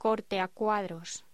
Locución: Corte a cuadros
voz